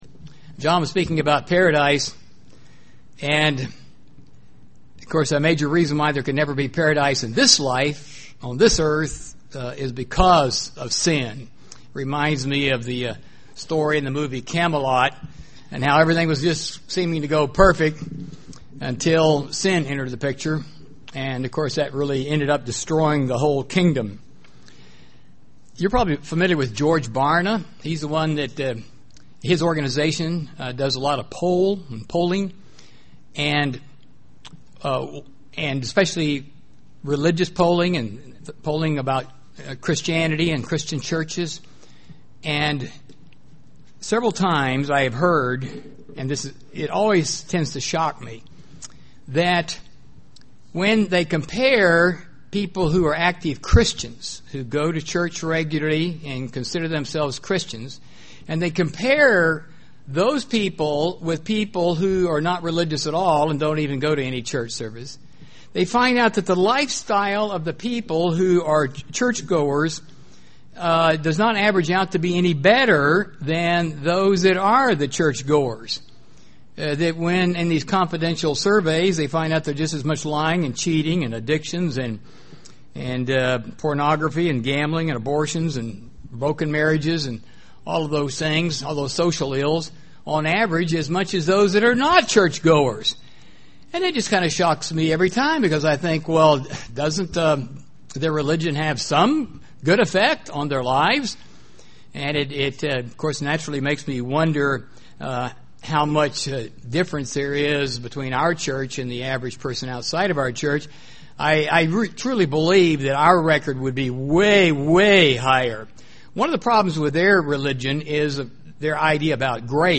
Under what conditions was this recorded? Given in Burlington, WA